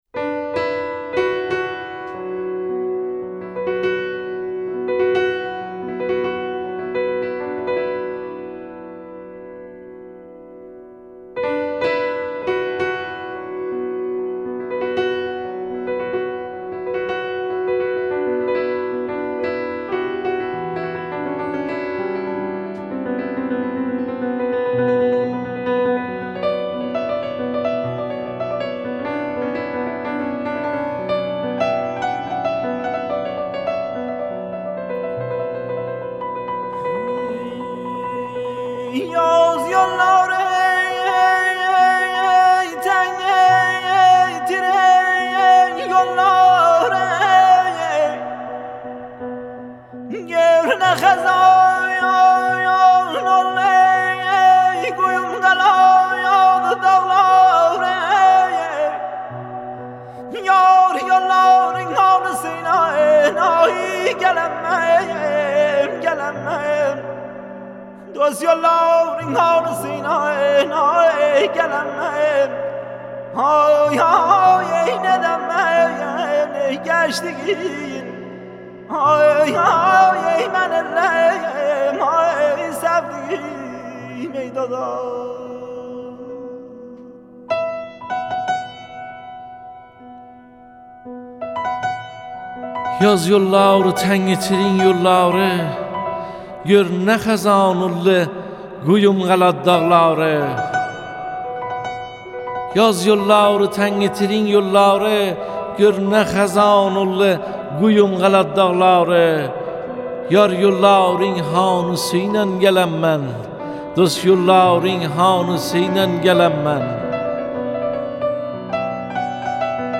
پیانو